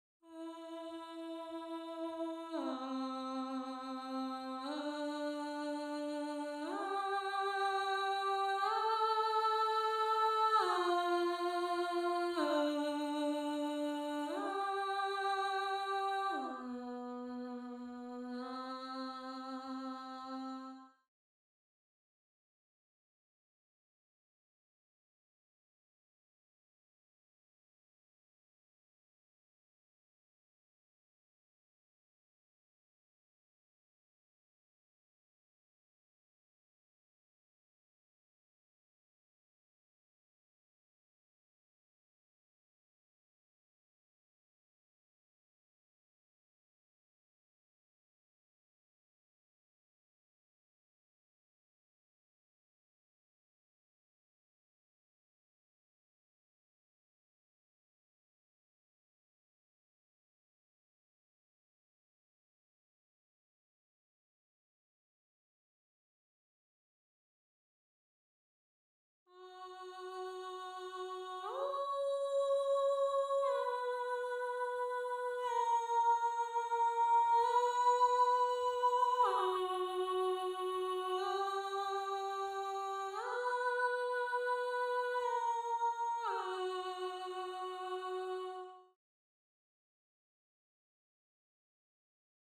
2. ALTO (Alto/Alto)
gallon-v3s4-21-Alto_0.mp3